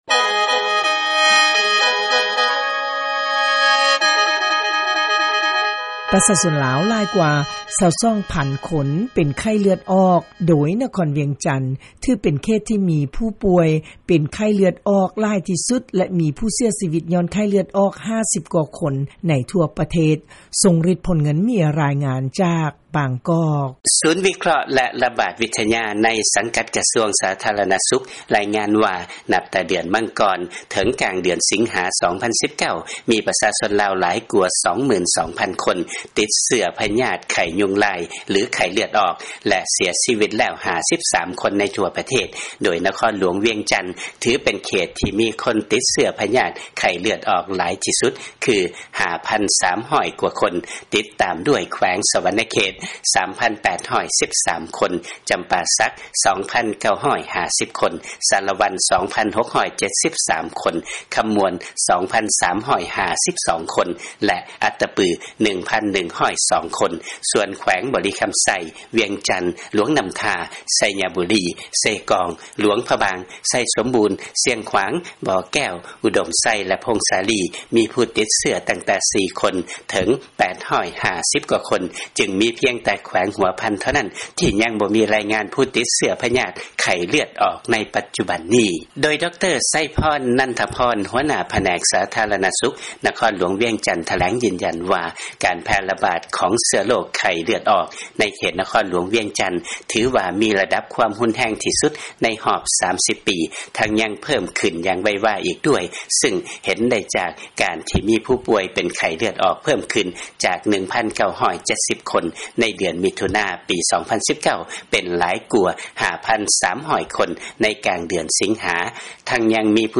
ເຊີນຟັງລາຍງານ ປະຊາຊົນ ຫຼາຍກວ່າ 22,000 ຄົນ ຕິດເຊື້ອພະຍາດ ໄຂ້ເລືອດອອກ ຊຶ່ງ ເຂດວຽງຈັນ ມີຄົນປ່ວຍຫຼາຍທີ່ສຸດ